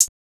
TS HiHat_4.wav